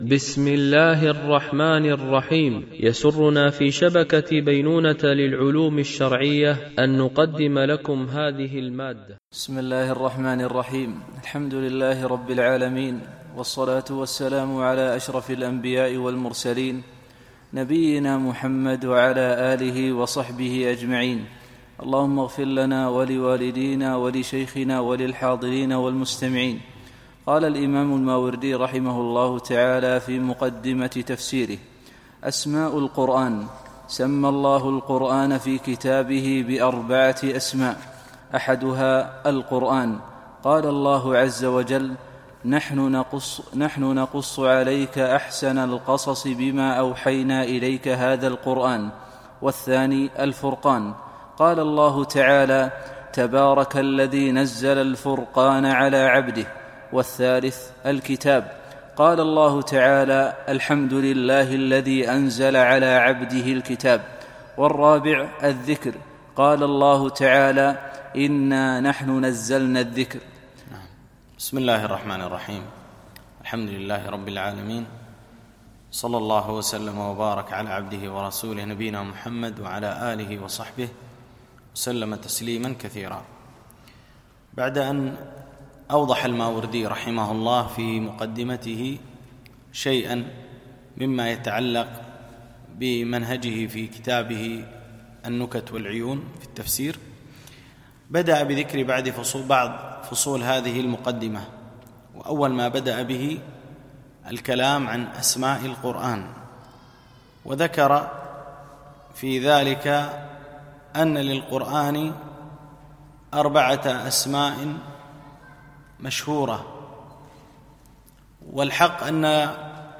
شرح مقدمة الماوردي في تفسيره النكت والعيون ـ الدرس 02